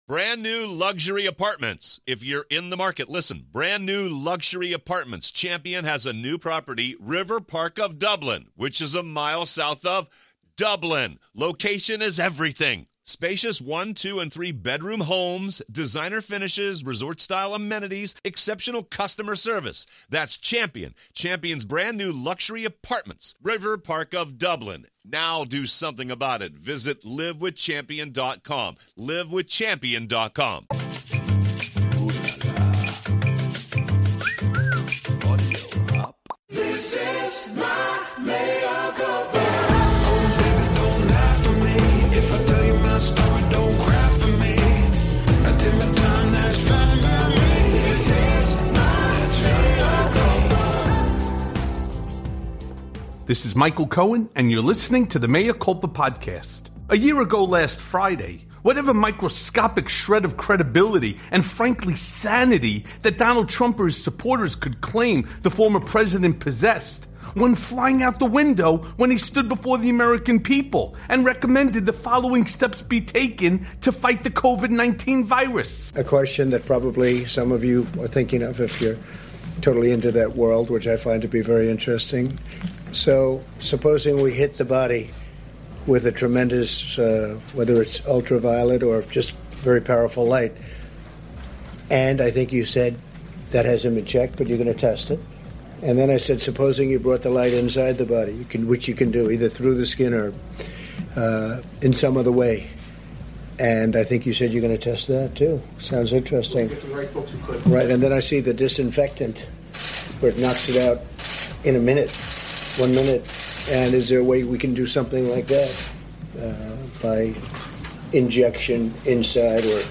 It’s Been 1 Yr Since Trump Told America to Drink Bleach + A Conversation with Richard Painter